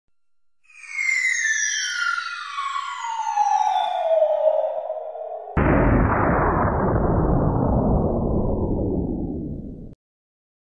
Tiếng Ném quả bom, lụ đạn và tiếng Nổ
Tung Tung Sahur… sound effect Tiếng Ném quả lụ đạn, quả bom Vèo….
Thể loại: Đánh nhau, vũ khí
Description: Âm thanh ném bom, lựu đạn và tiếng nổ, tiếng bùng phát, tiếng bùng nổ, tiếng oanh tạc, tiếng đạn vang dội, tiếng chấn động dữ dội...
tieng-nem-qua-bom-lu-dan-va-tieng-no-www_tiengdong_com.mp3